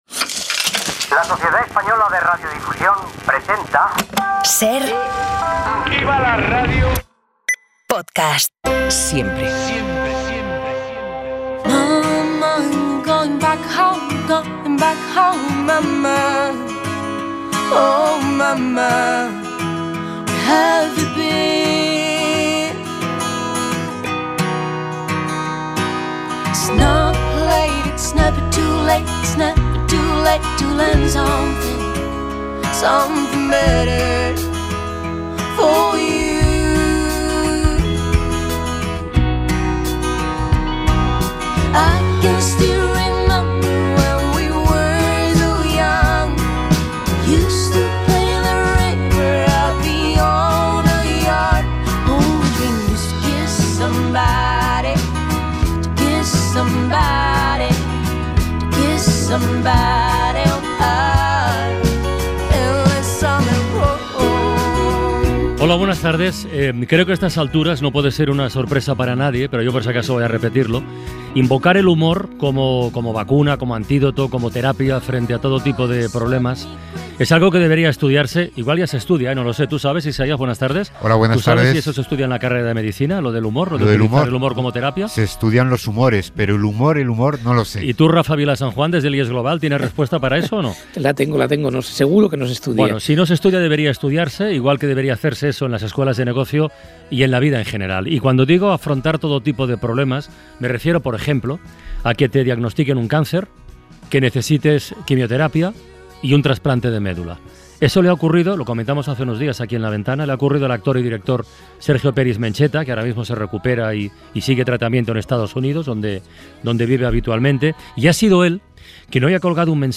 Entrevistamos a una jurista para conocer la sentencia por agresión sexual de Dani Alves.